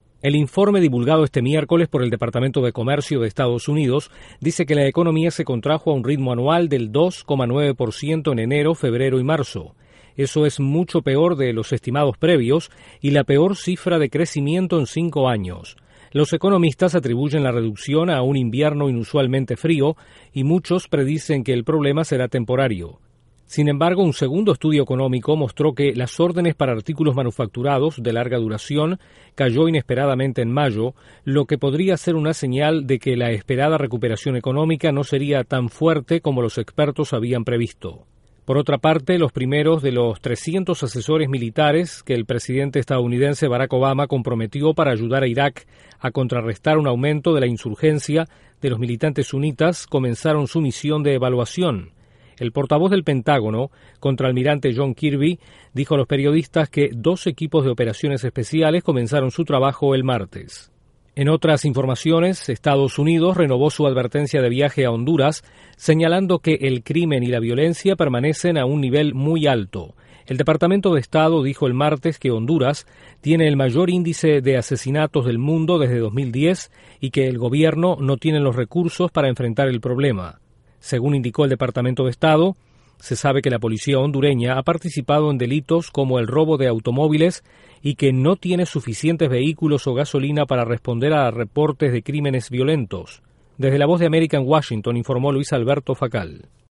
La economía de Estados Unidos se contrajo a un ritmo más rápido de lo previsto a comienzos de este año. Ésta y otras noticias desde la Voz de América en Washington